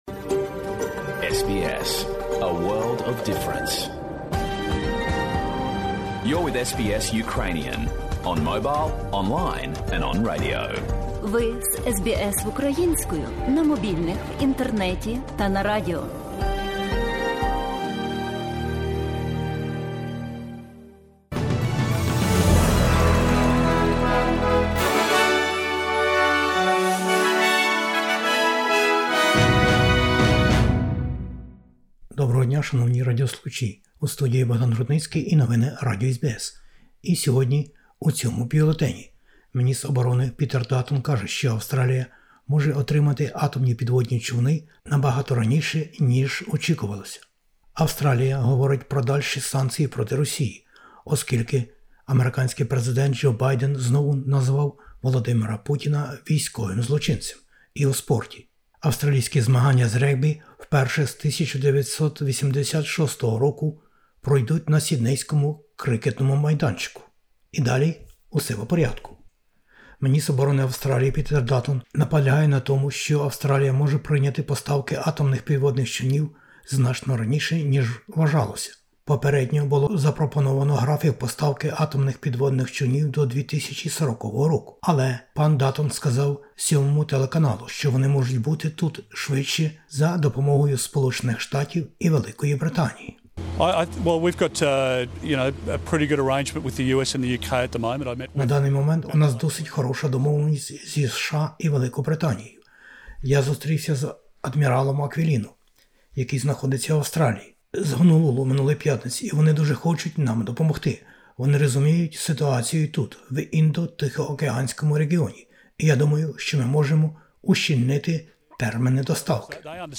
Бюлетень новин українською мовою. Підводні новітні човни і ракети можуть прибути до Австралії значно раніше, ніж планувалося. Світ засуджує російські збройні сили та В. Путіна за знущання над цивільними людьми і вбивства невинних чоловіків, жінок і дітей в українських містах і селах. Нещастя у Блакитних горах.